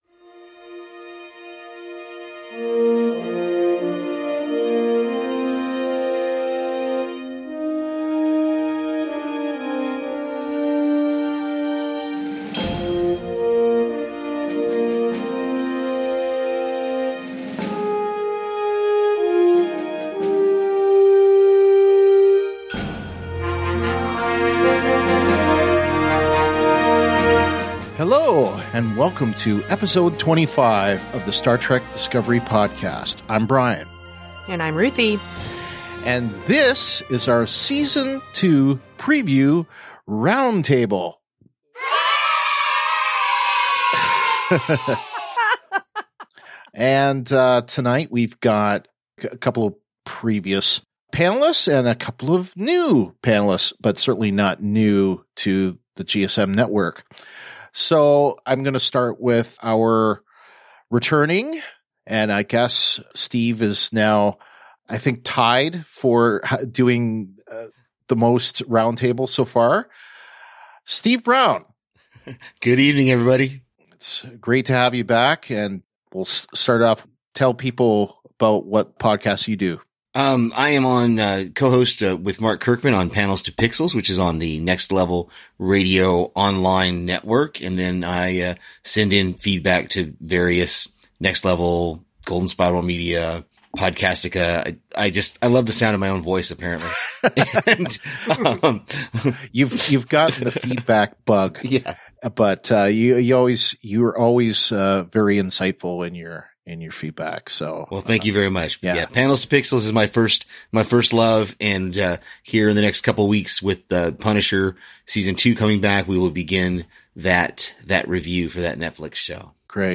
The Star Trek: Discovery Podcast is an in-depth discussion about Star Trek: Discovery, shown weekly on CBS All Access in the U.S., Space in Canada and Netflix everywhere else.